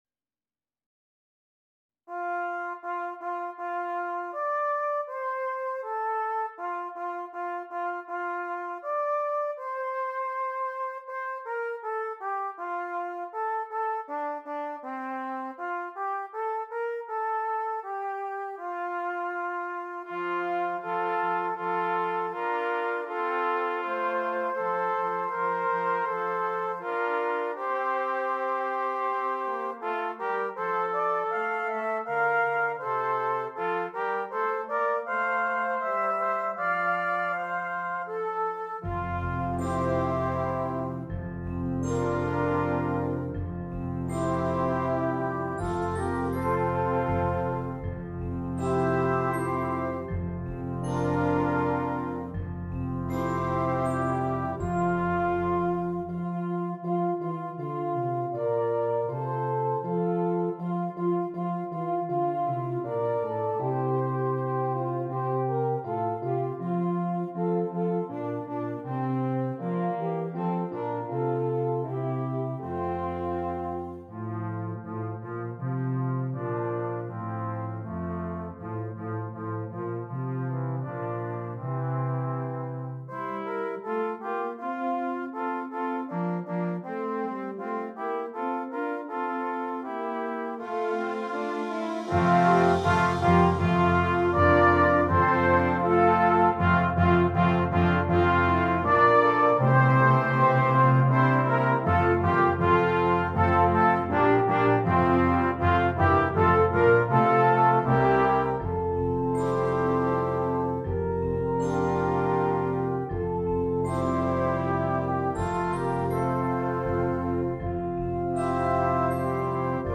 Brass Choir
Traditional Carol
This piece is flowing and smooth, quiet and reflective.